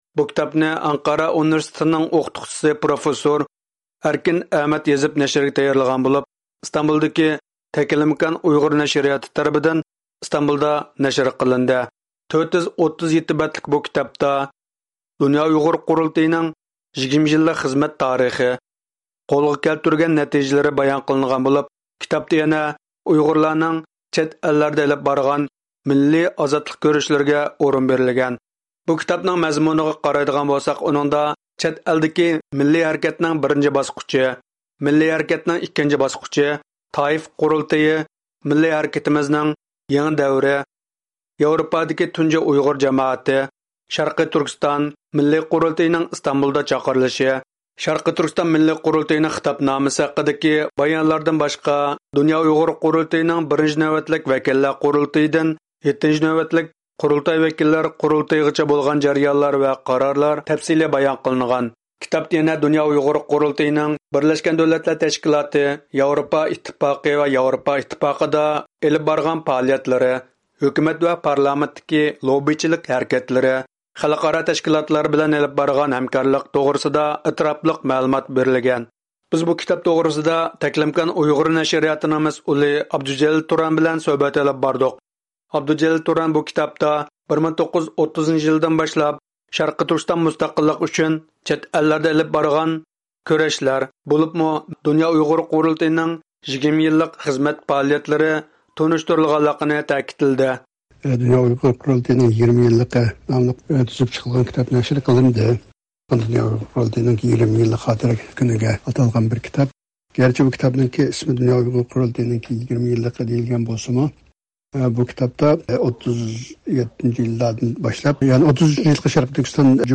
سۆھبەت ئېلىپ باردۇق.